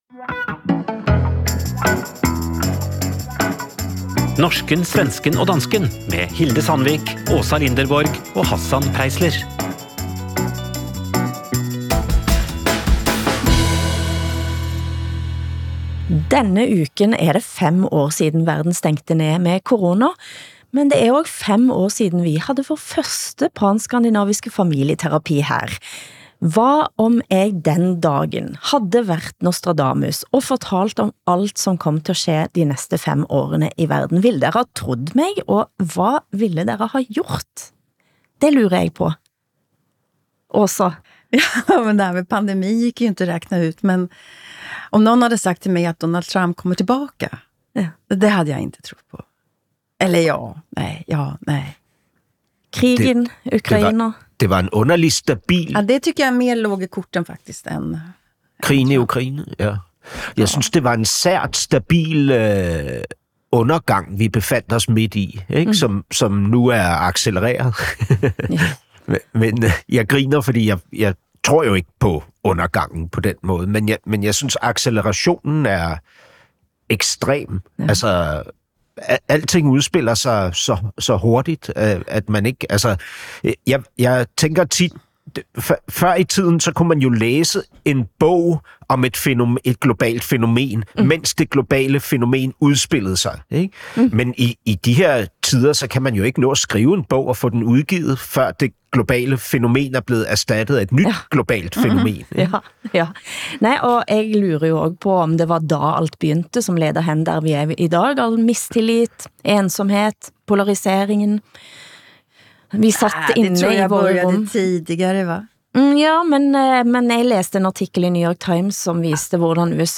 Ugentlig, pan-skandinavisk familieterapi med forfatter og journalist Åsa Linderborg (S), radiovært og forfatter Hassan Preisler (DK) og programleder/samtaleterapeut Hilde Sandvik (N).